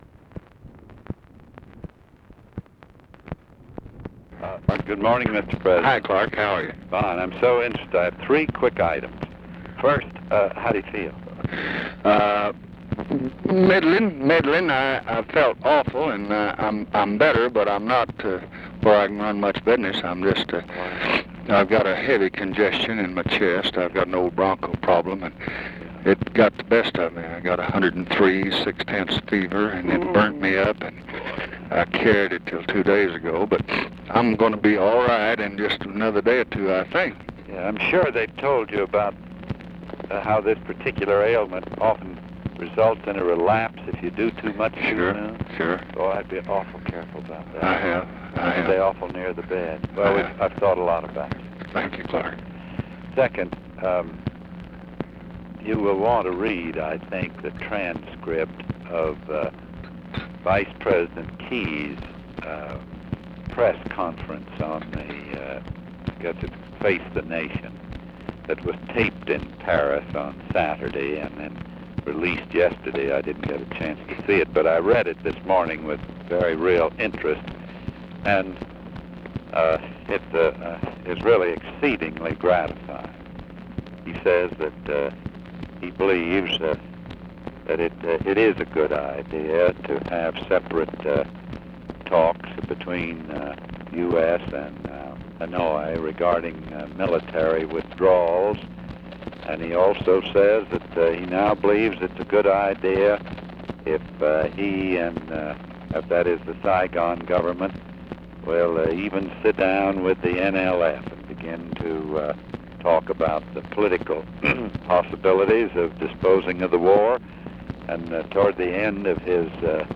Conversation with CLARK CLIFFORD, December 23, 1968
Secret White House Tapes